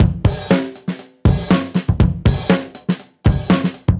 Index of /musicradar/dusty-funk-samples/Beats/120bpm
DF_BeatC_120-04.wav